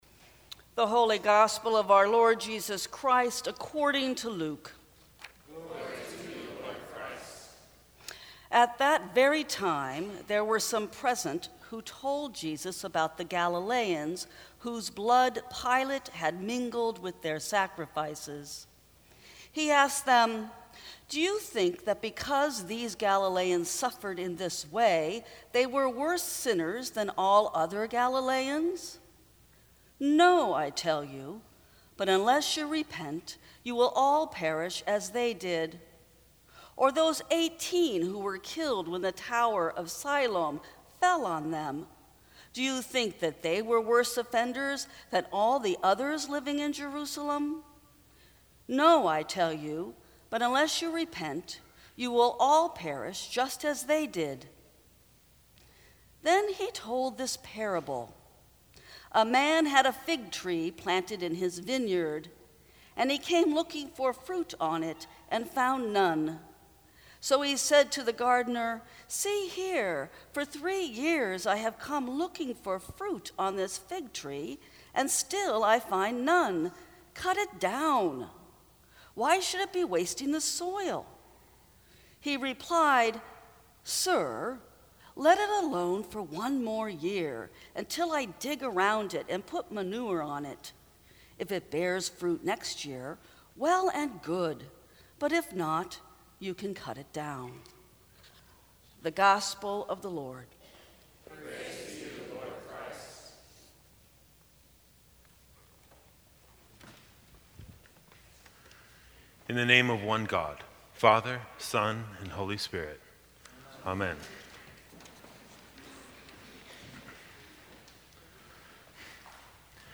Sermons from St. Cross Episcopal Church